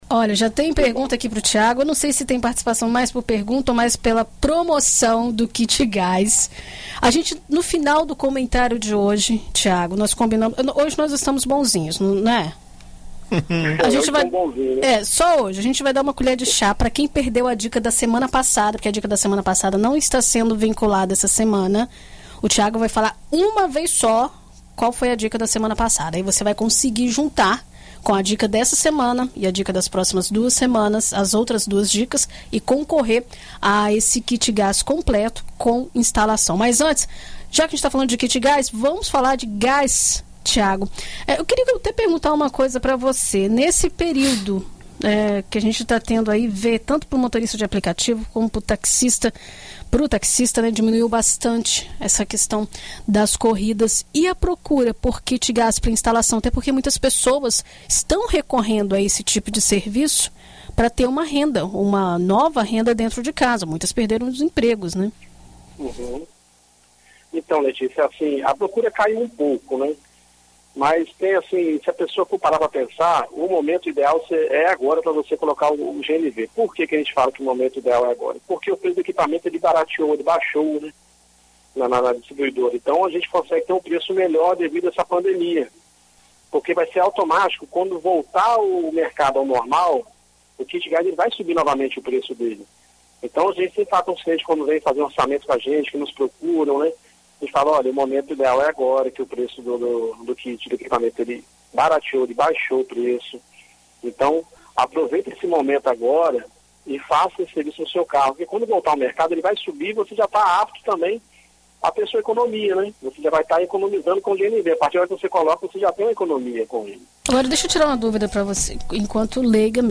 Confira o comentário na BandNews FM: